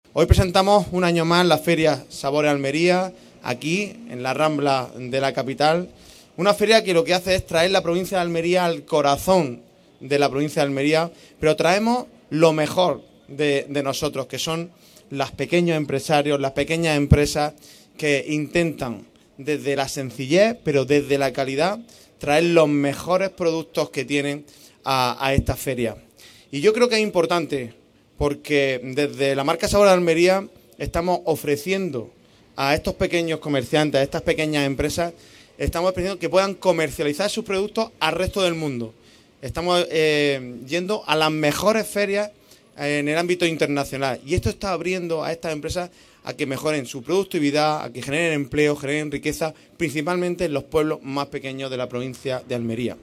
Esta mañana, el presidente de la Diputación de Almería, José Antonio García Alcaina,          y la alcaldesa de Almería, María del Mar Vázquez, acompañados por el diputado de    Presidencia y Promoción de la Provincia, Carlos Sánchez, y la concejala de Comercio     del Ayuntamiento de Almería y diputada provincial, Lorena Nieto, junto a otras         autoridades, han inaugurado la Feria Sabores Almería.
JOSE-ANTONIO-GARCIA-PRESIDENTE-DIPUTACION-SABORES-ALMERIA-NAVIDAD.mp3